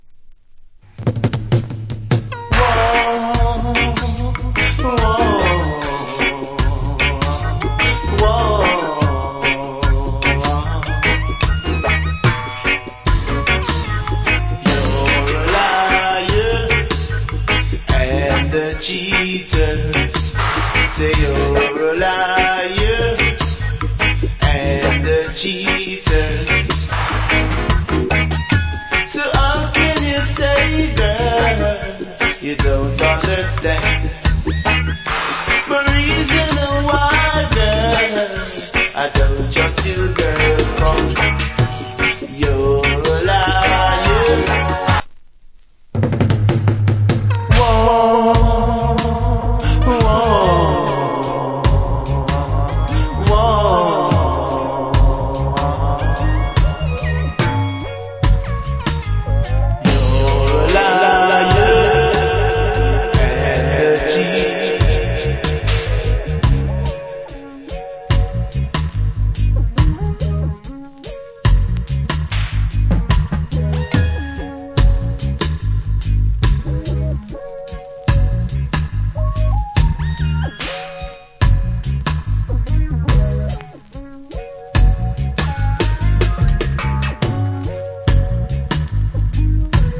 80's Good Reggae Vocal.